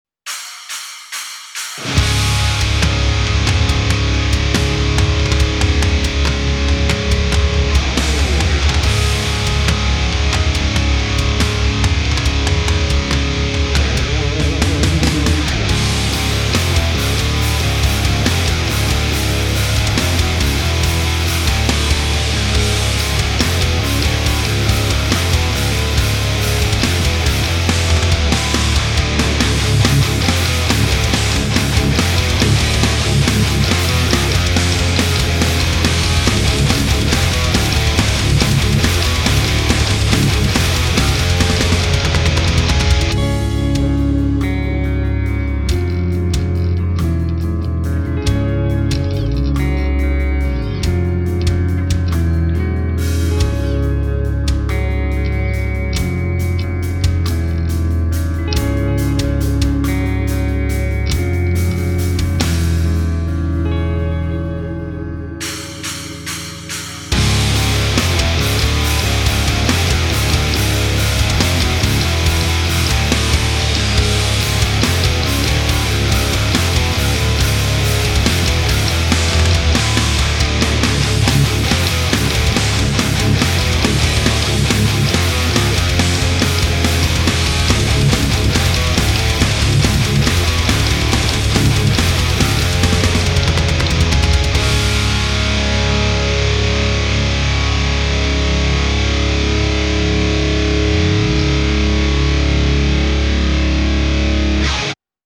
original metal track